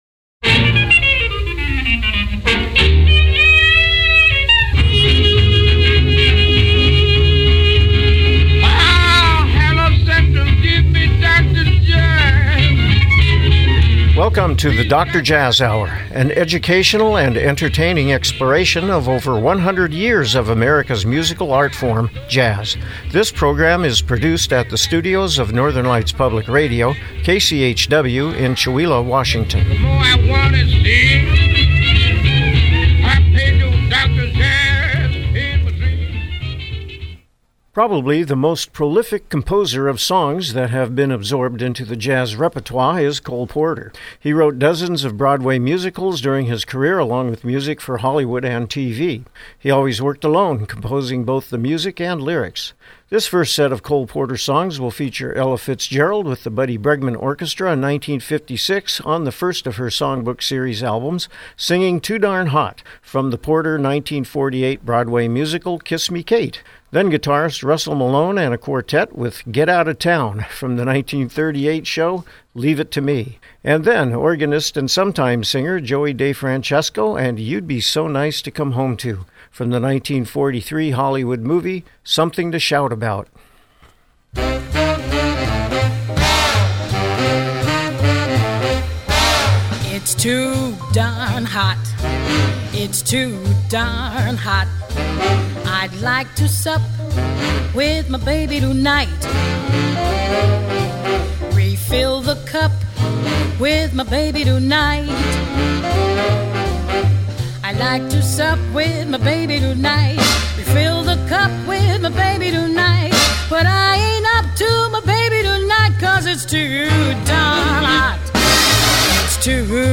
Program Type: Music Speakers